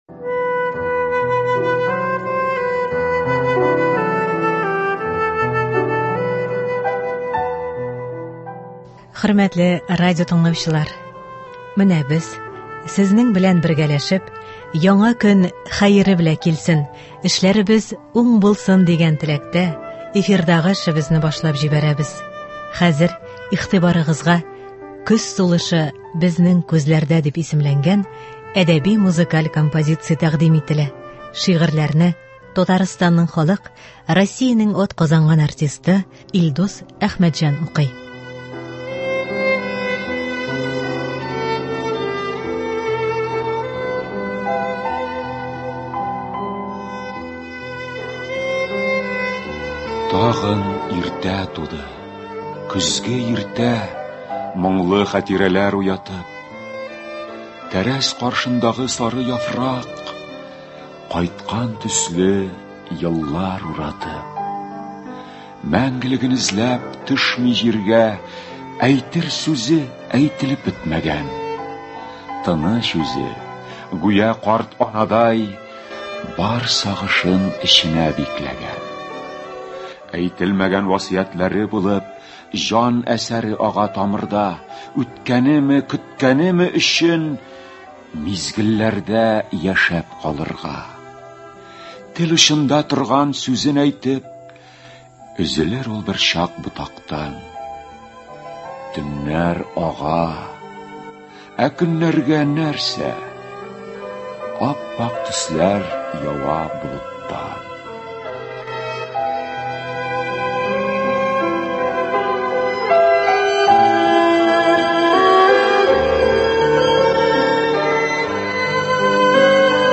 Әдәби-музыкаль композиция (21.09.24)